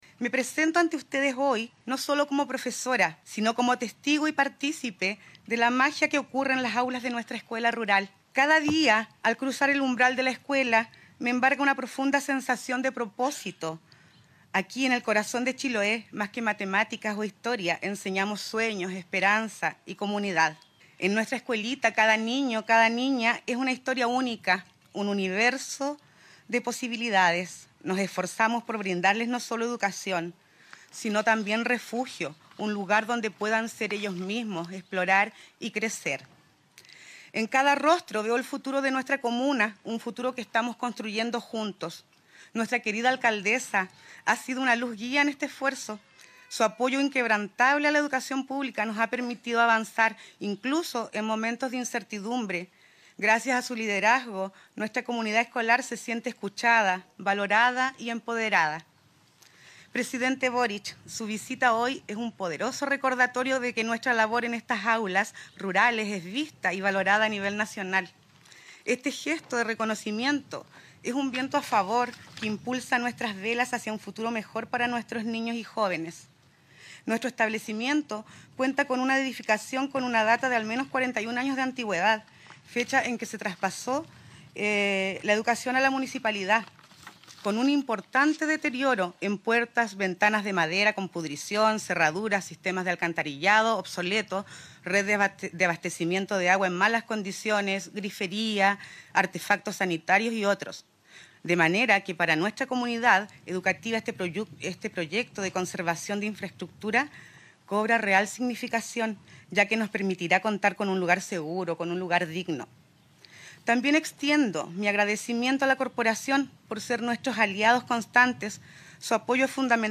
El Presidente de la República, Gabriel Boric; el ministro de Educación, Nicolás Cataldo, y la alcaldesa de Curaco de Vélez, Javiera Yáñez, encabezaron este miércoles 31 de enero la ceremonia de inicio de las obras de conservación de la escuela rural del sector de Huyar Alto, un proyecto que beneficiará a 54 alumnos y alumnas y toda la comunidad educativa, con una inversión de casi $450 millones.